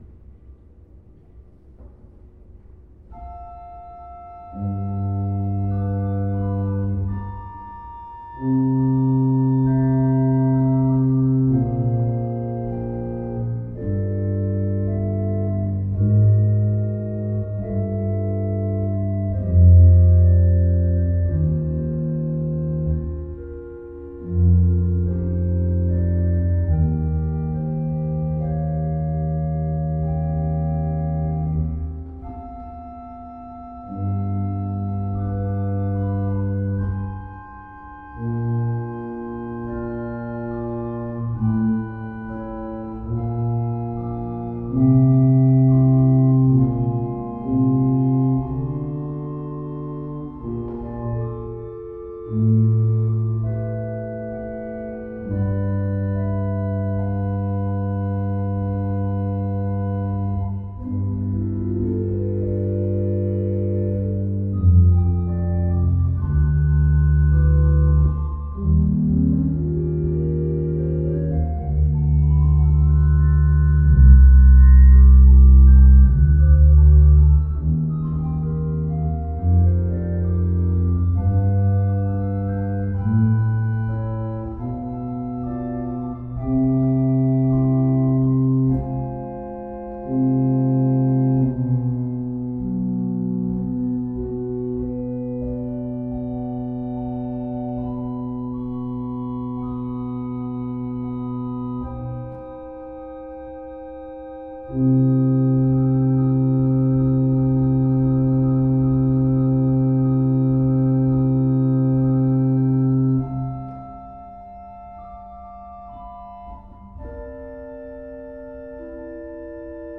Choralvorspiele (RG, KG, rise up, Negro Spirituals):